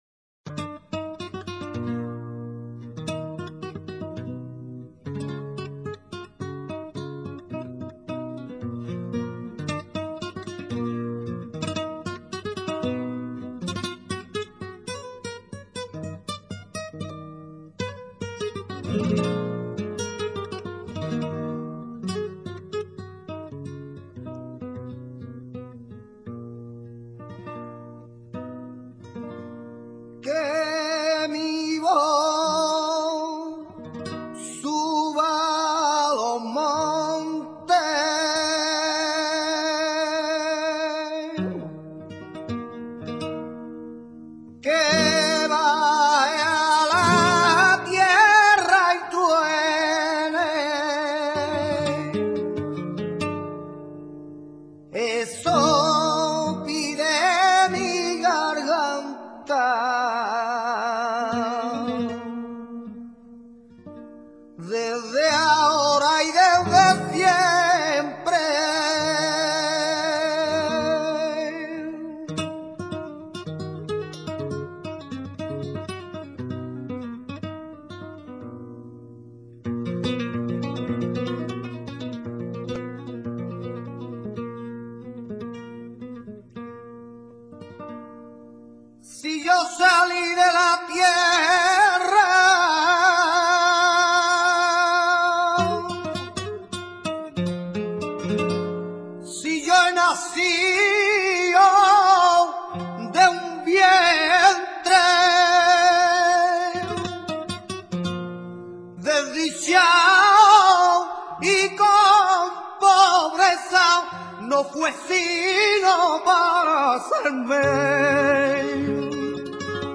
ROMANCE.
romance.mp3